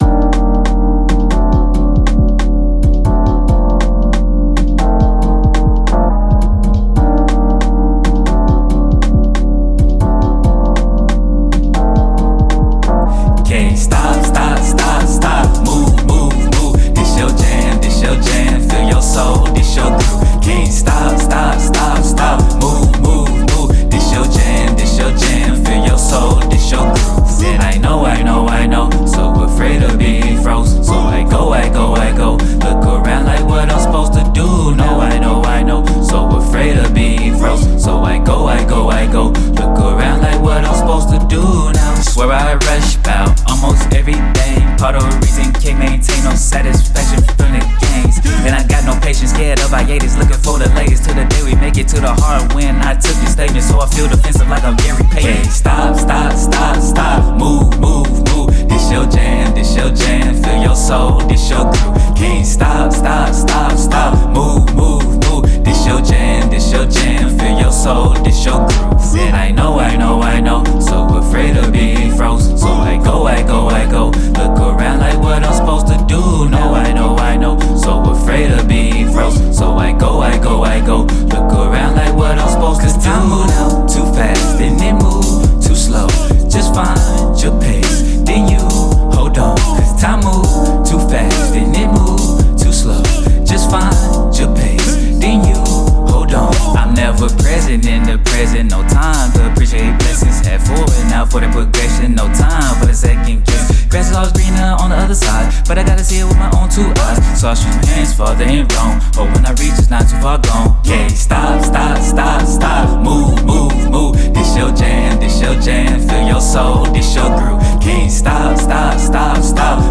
Genre: afrobeat.